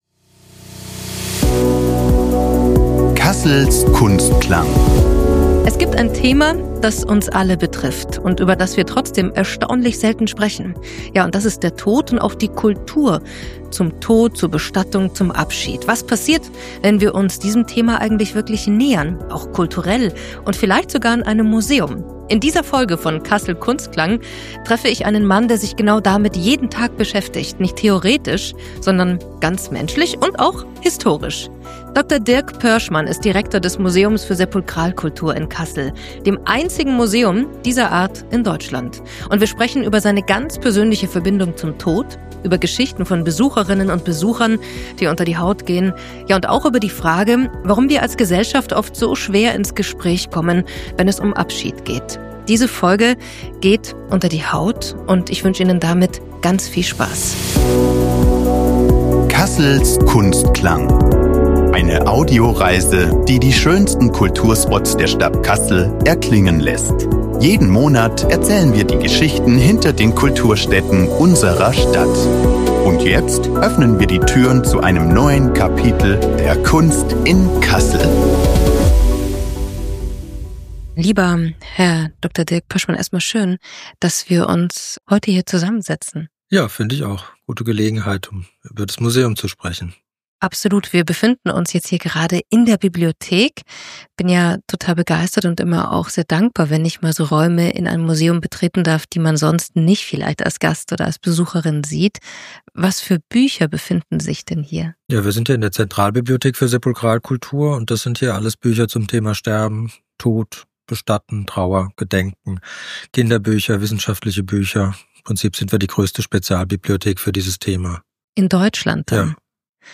Ein Gespräch über persönliche Begegnungen mit dem Tod, bewegende Geschichten von Besucherinnen und Besuchern – und darüber, warum unsere Gesellschaft oft sprachlos bleibt, wenn es um Abschied geht. Außerdem: Warum es in Deutschland nur ein einziges Museum gibt, das sich diesem Thema widmet – und was das über uns aussagt.